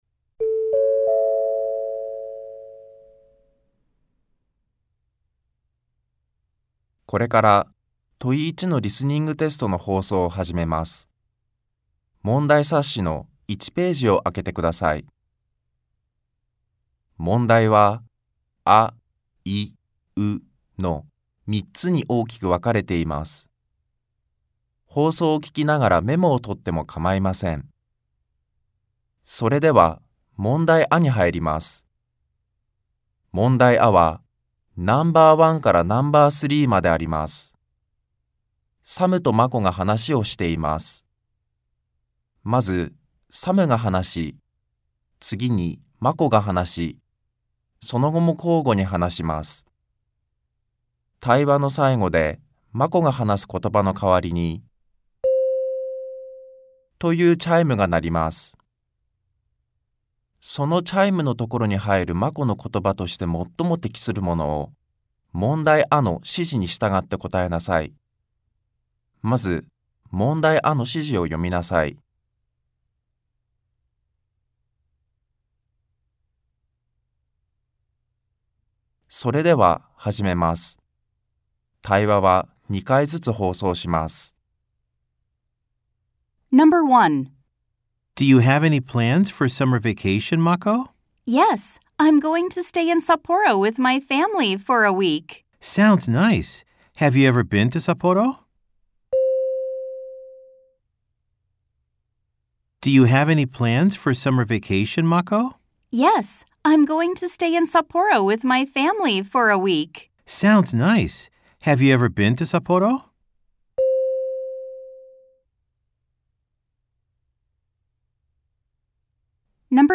・リスニング音源（MP3：8,723KB）